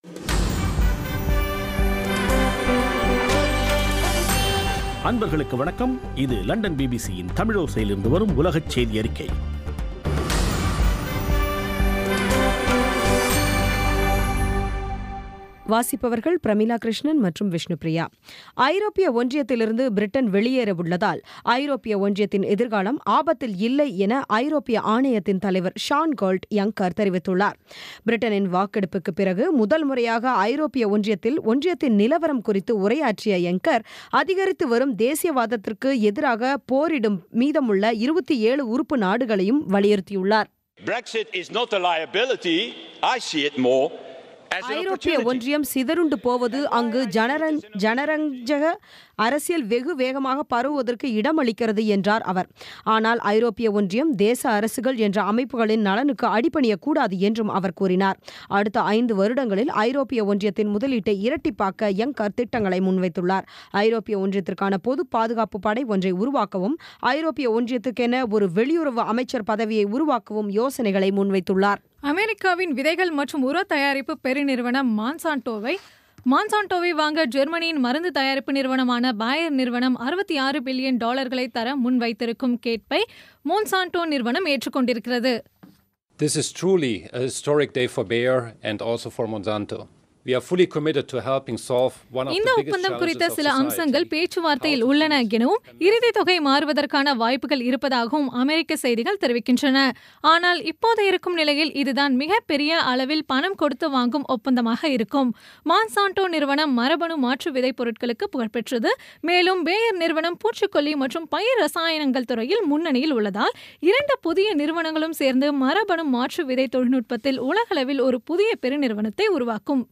பி பி சி தமிழோசை செய்தியறிக்கை (14/09/2016)